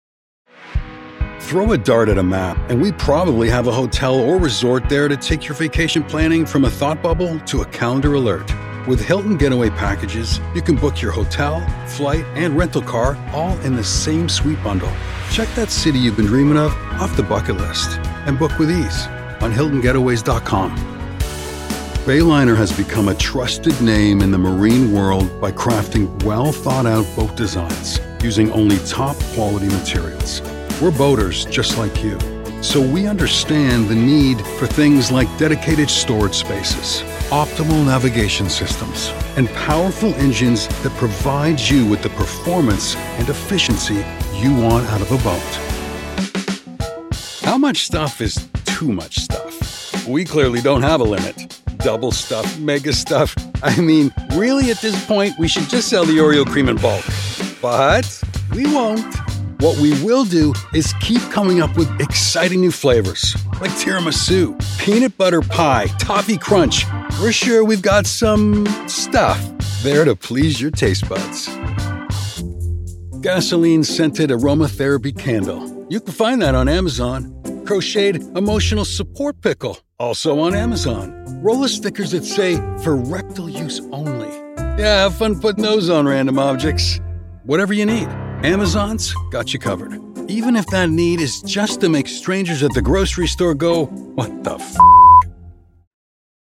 Friendly, Warm, Conversational.
Corporate